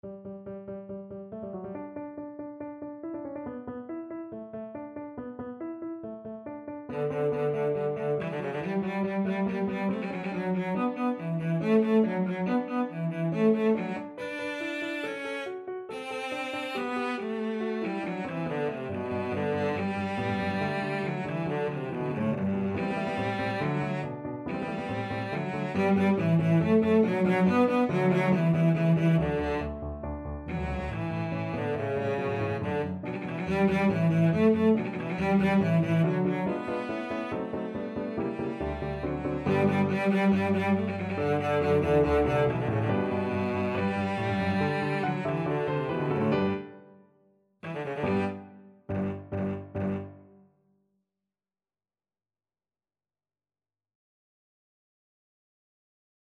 Cello version
= 140 Allegro (View more music marked Allegro)
4/4 (View more 4/4 Music)
Classical (View more Classical Cello Music)